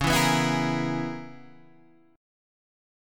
C#m13 chord {9 7 9 9 9 6} chord